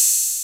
Open Hats
OpenHH SwaggedOut 3.wav